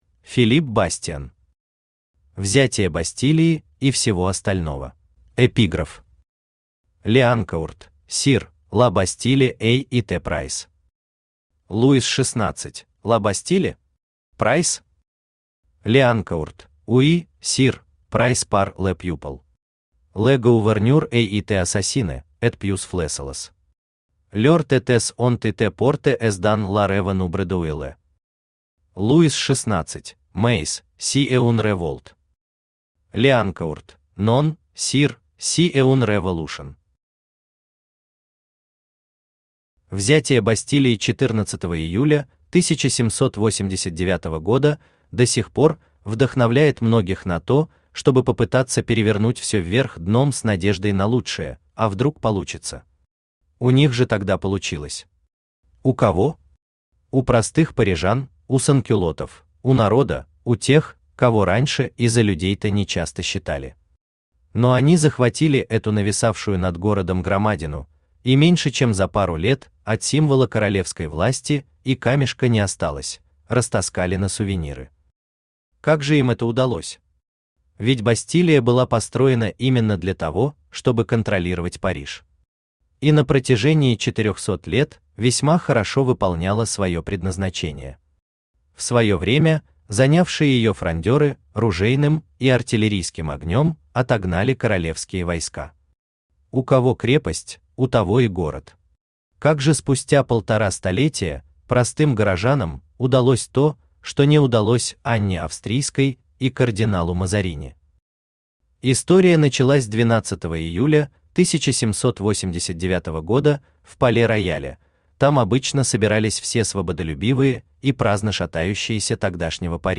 Аудиокнига Взятие Бастилии и всего остального | Библиотека аудиокниг
Aудиокнига Взятие Бастилии и всего остального Автор Филипп Бастиан Читает аудиокнигу Авточтец ЛитРес.